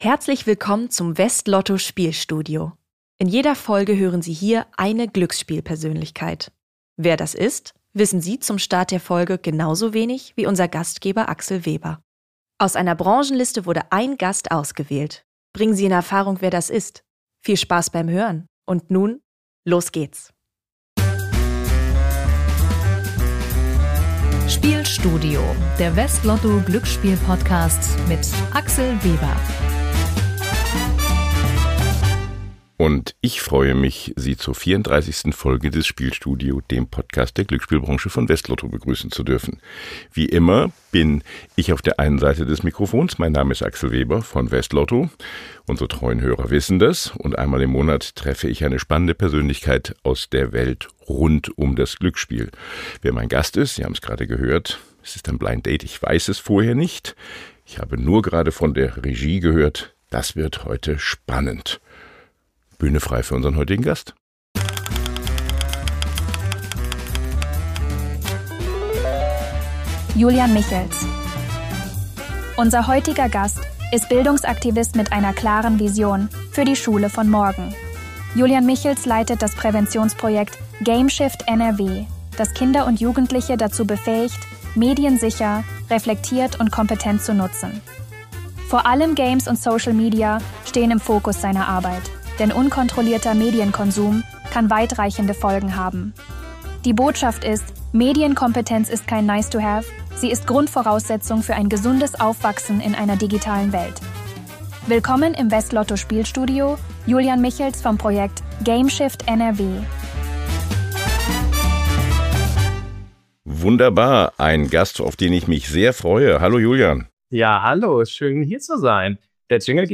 Hinweis: Diese Folge enthält einen Zuspieler, der von einer KI-Stimme gesprochen wird.